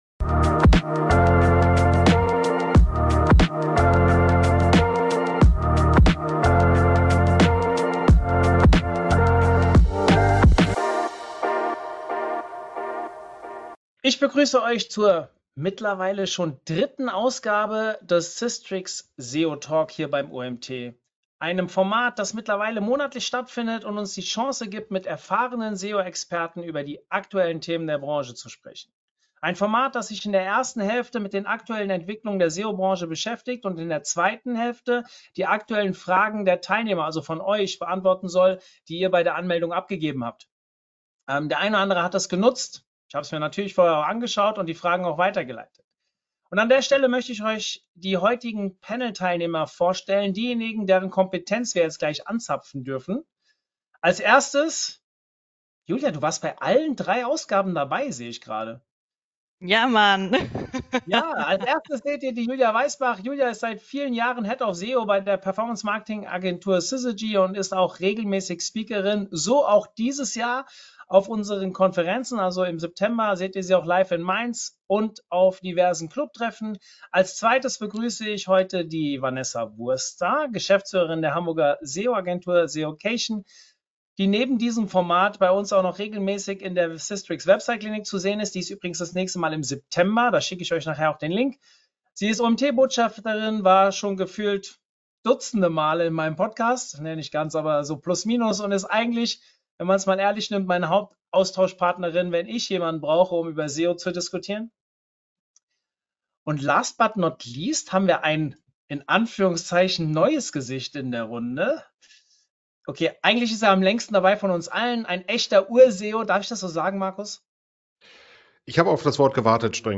Blues-Musiker